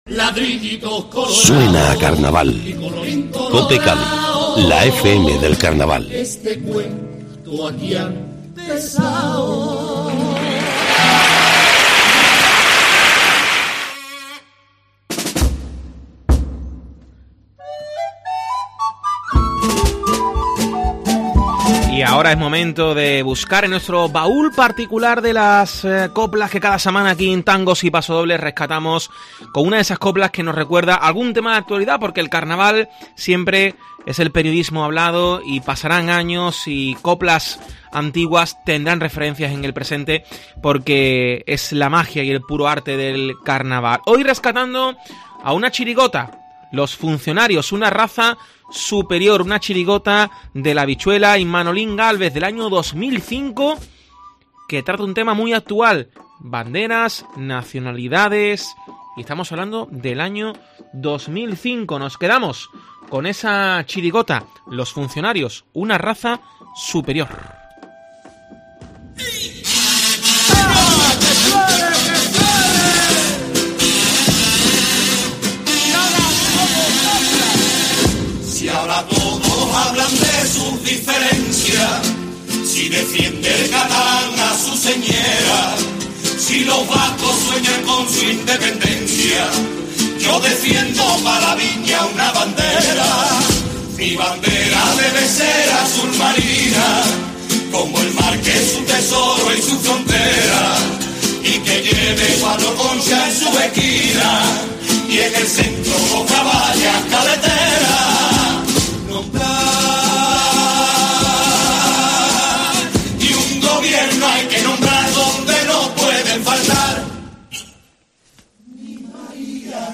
pasodoble
chirigota
comparsa